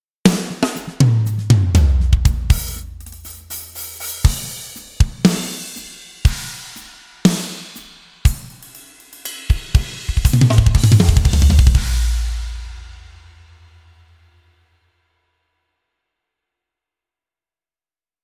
Качество: Моно 48 кГц 24 бита
Описание: Ударная установка
Gigantic low-tuned drums cut through with massive impact, while shimmering cymbals bring energy and brilliance, adding waves of polish and sheen.
Только ударные #1